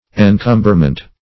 Encumberment \En*cum"ber*ment\, n.